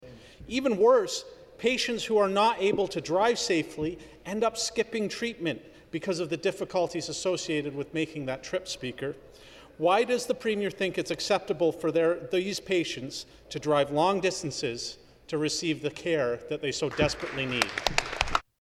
The New Democratic MPP for Kingston and the Islands, Ian Arthur, rose in the Ontario legislature to call on the government to establish a unit at TMH.